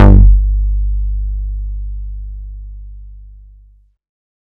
Bass Pluck - R.I.P. SCREW SHARP [ G ].wav